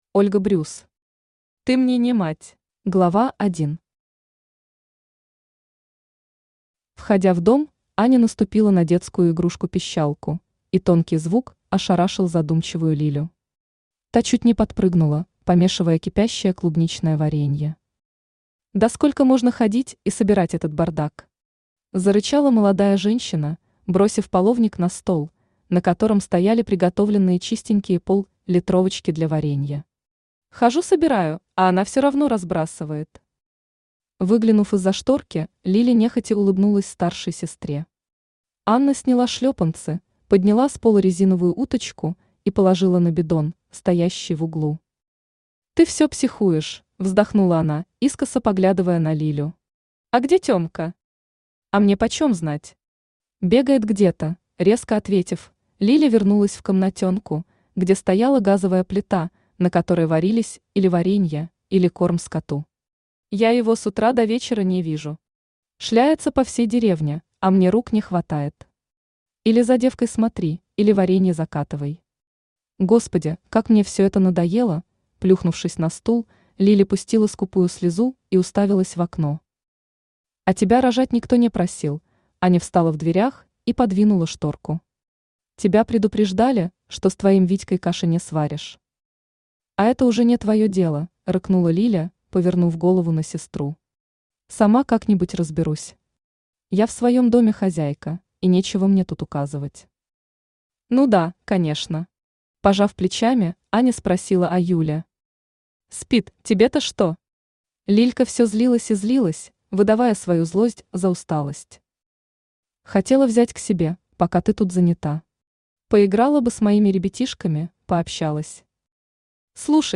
Aудиокнига Ты мне не мать! Автор Ольга Брюс Читает аудиокнигу Авточтец ЛитРес.